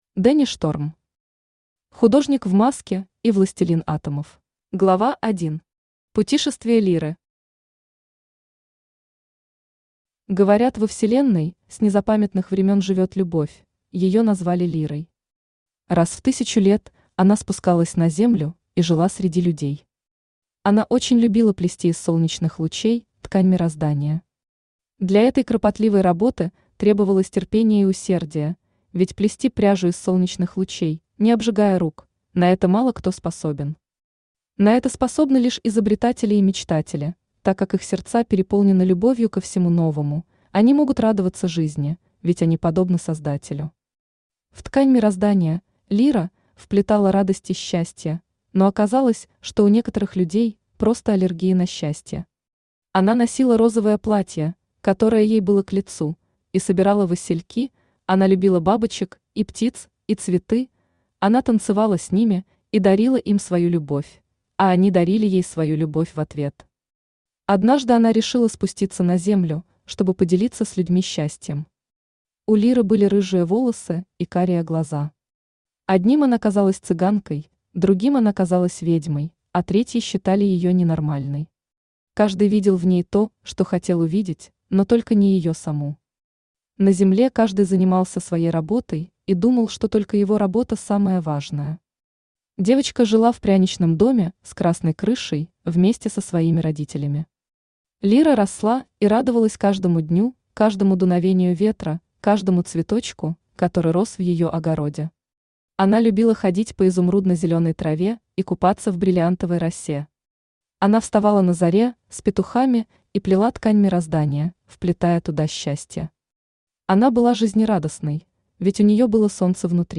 Аудиокнига Художник в маске и Властелин Атомов | Библиотека аудиокниг
Aудиокнига Художник в маске и Властелин Атомов Автор Дэнни Шторм Читает аудиокнигу Авточтец ЛитРес.